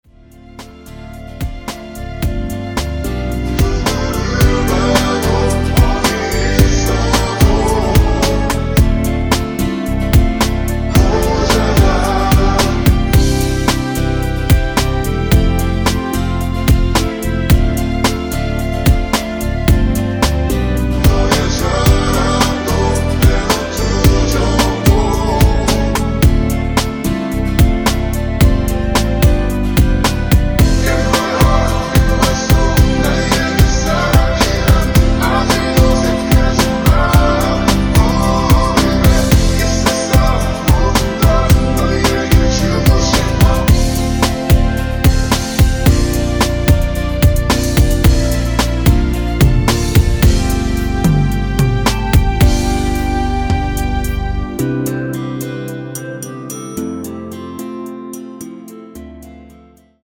원키에서(-3)내린 멜로디와 코러스 포함된 MR입니다.(미리듣기 확인)
Bb
앞부분30초, 뒷부분30초씩 편집해서 올려 드리고 있습니다.
중간에 음이 끈어지고 다시 나오는 이유는